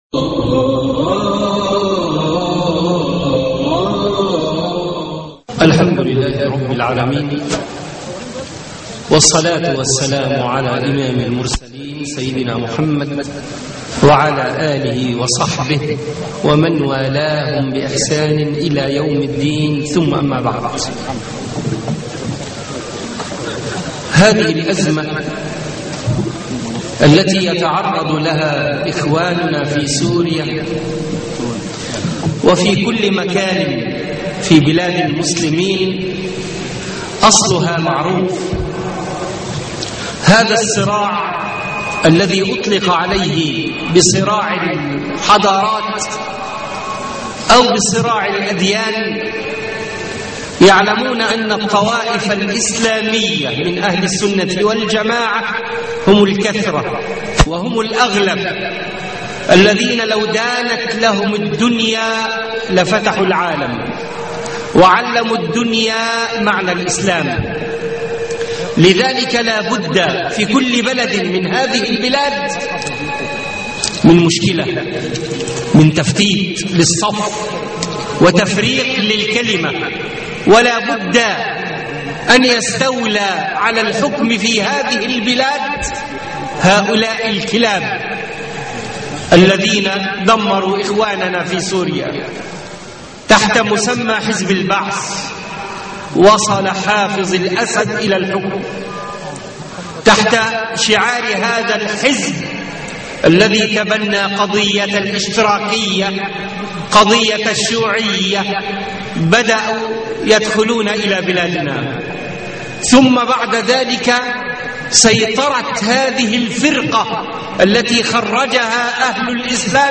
لقاء الرابطة العلمية بالسويس حول قضية سوريا (12/3/2012) الرابطة العلمية في السويس - فضيلة الشيخ محمد حسان